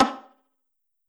select-difficulty.wav